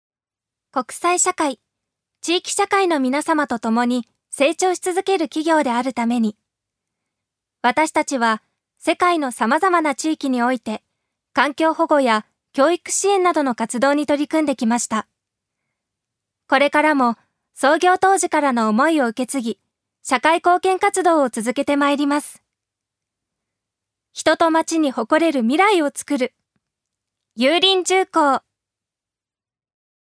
ジュニア：女性
ナレーション２